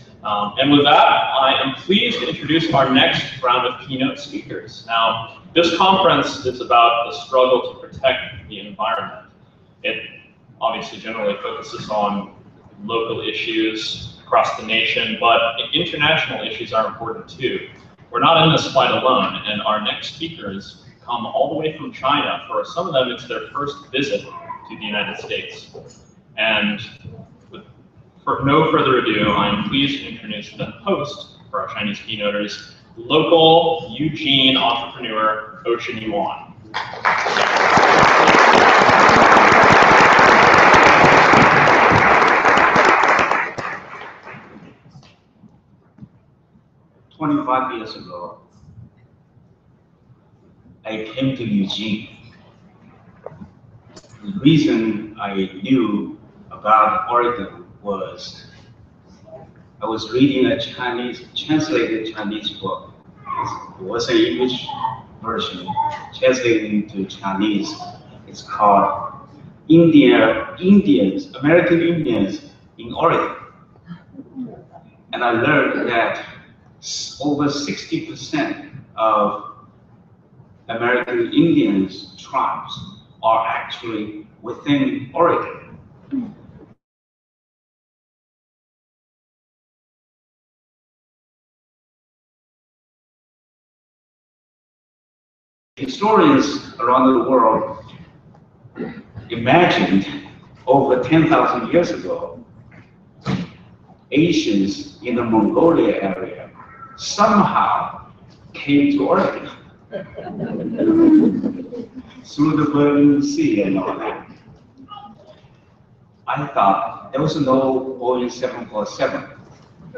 keynote